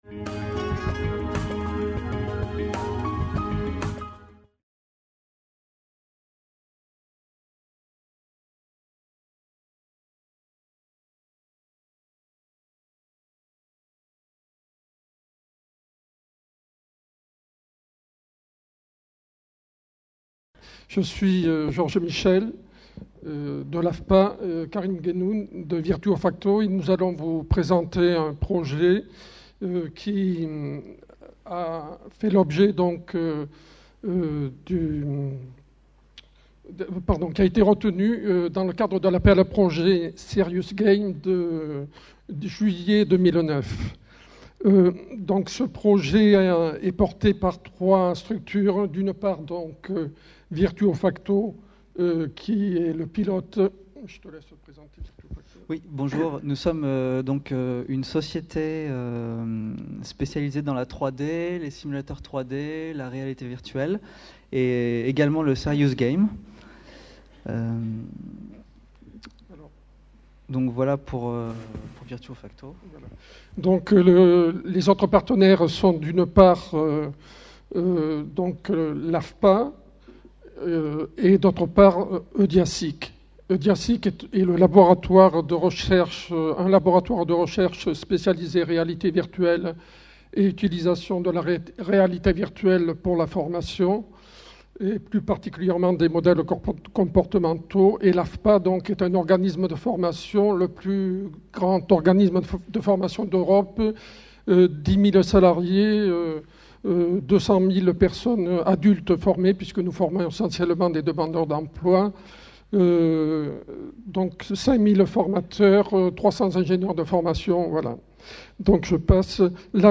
FORMATIC - Paris 2011 : Sim ADVF, un «serious game» pour former les professionnels du service à la personne à la prévention et à la réaction en situation de danger pour les enfants. Conférence enregistrée lors du congrès international FORMATIC PARIS 2011.